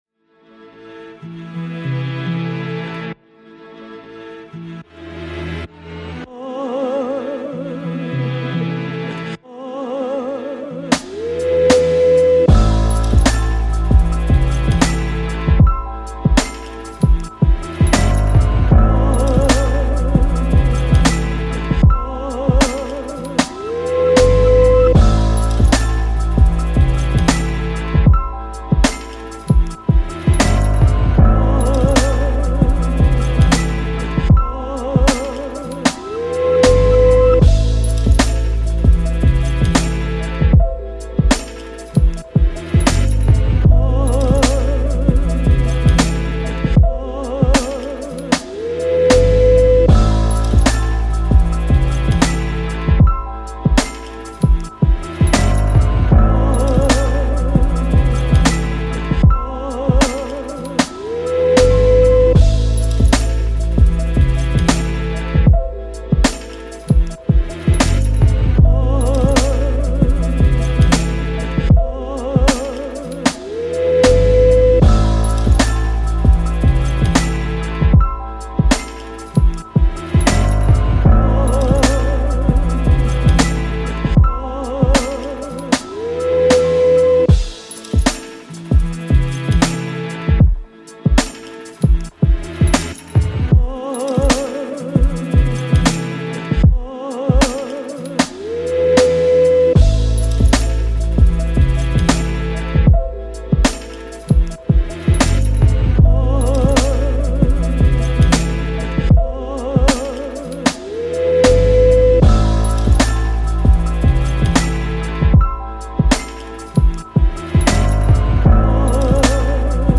Boom Bap Instrumentals